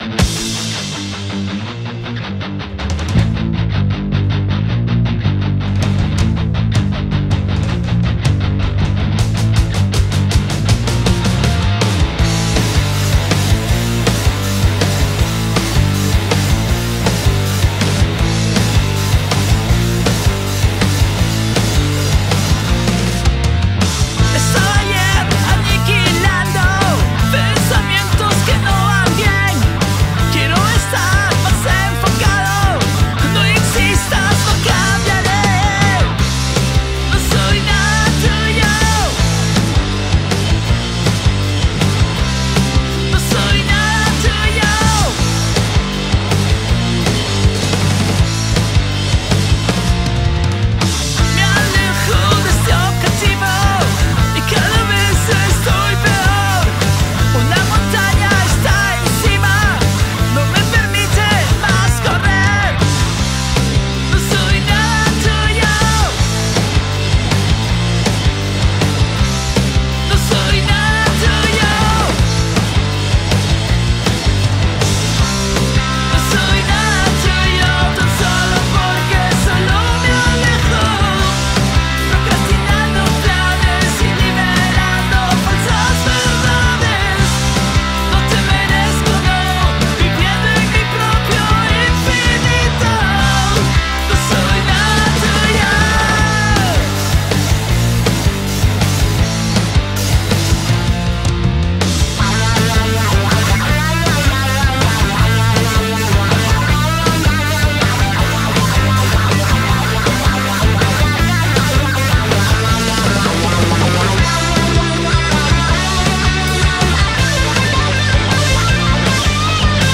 Pop music Rock